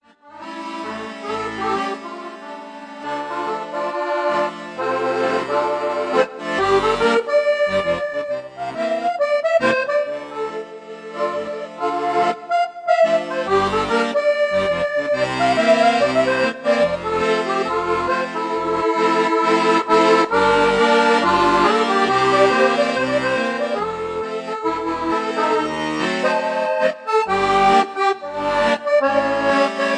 played on a Roland FR3 piano accordion set to French musette